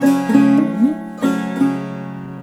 SAROD1    -L.wav